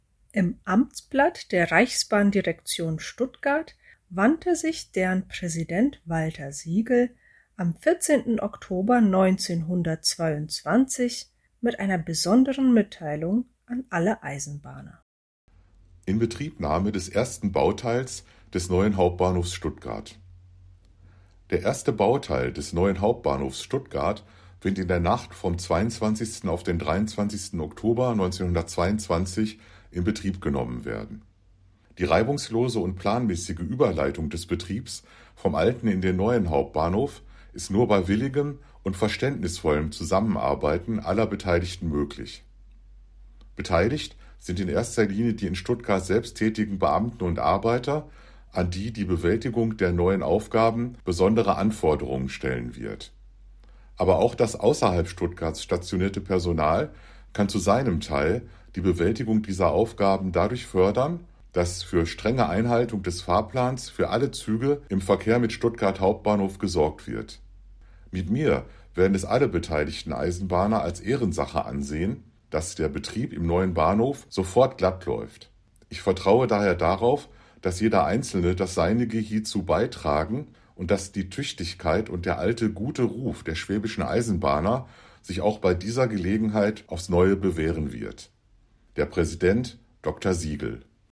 Hör mal hin! Die Übergabe des ersten Abschnitt des neuen HBF, Oktober 1922